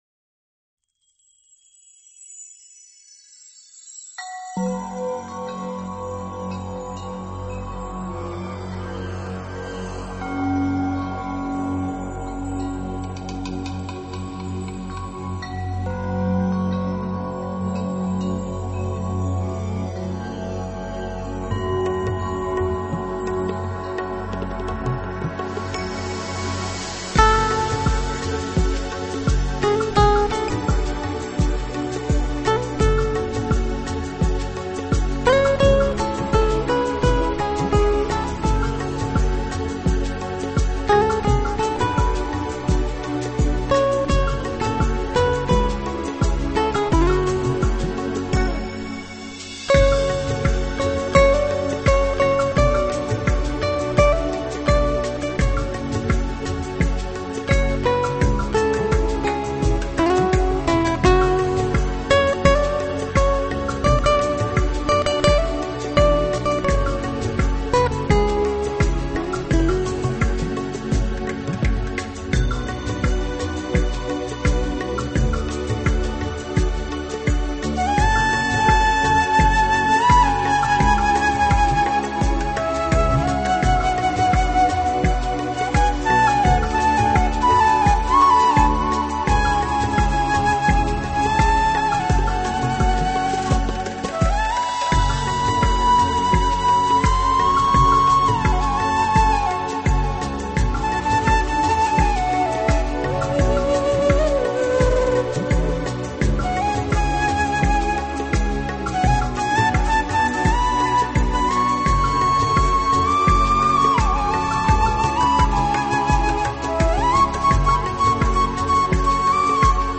吉他演奏
长笛